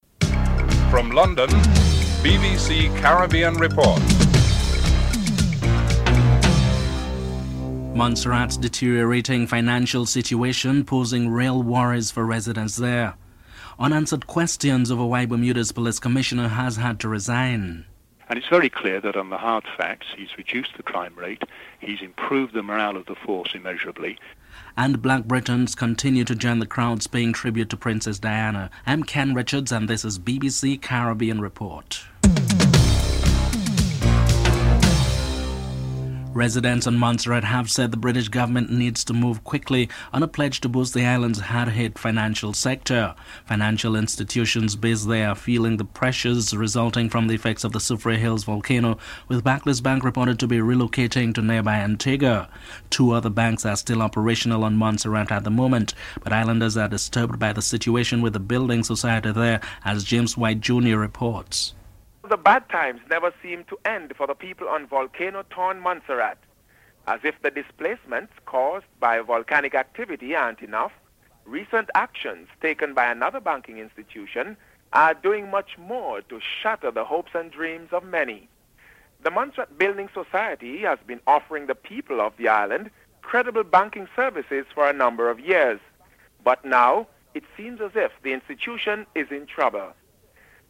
1. Headlines (00:00-00:35)
The Committee's Chairman, Conservative Member of Parliament Bowen Well is interviewed (04:45-07:47)